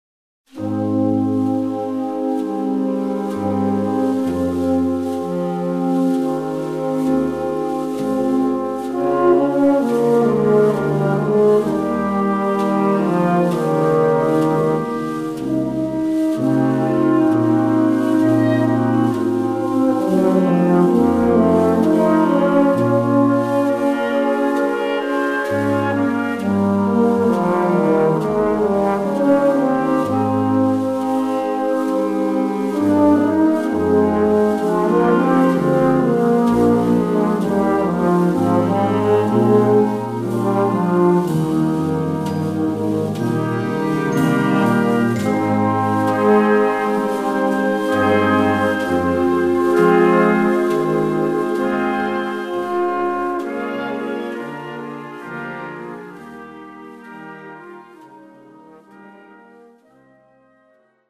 Stil: Kirchenmusik Arrangeur
Blasorchester